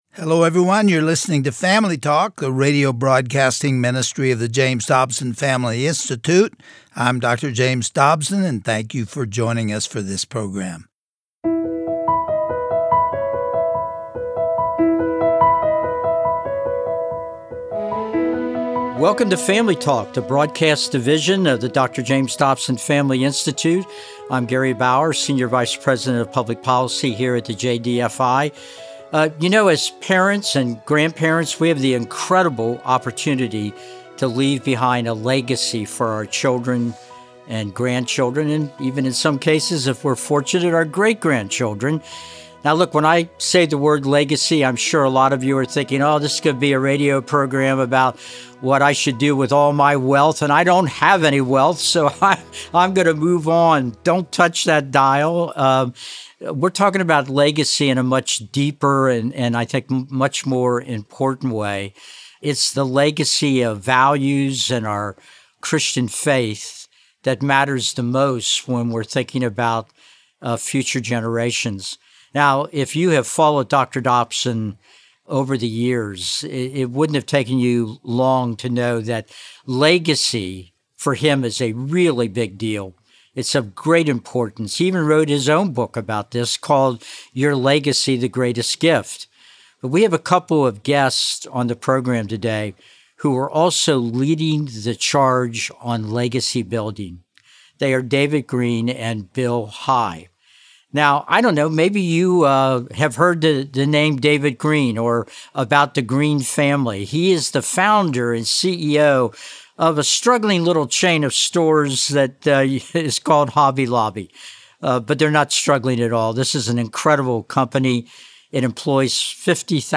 Host Gary Bauer